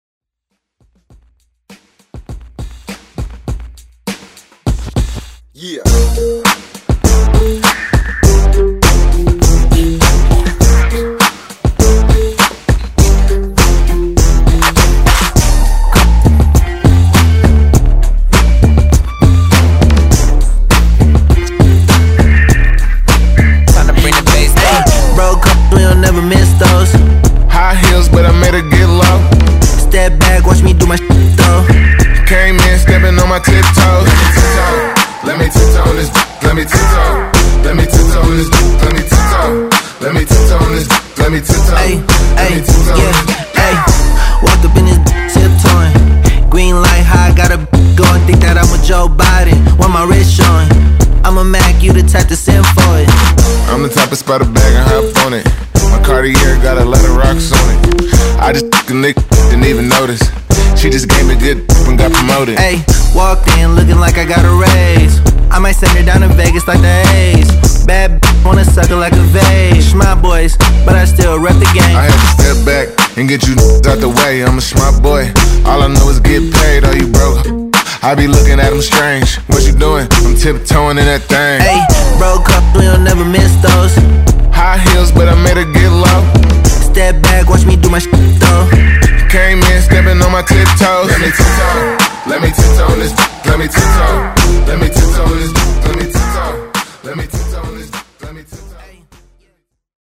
Genres: EDM , MASHUPS , TOP40
Clean BPM: 128 Time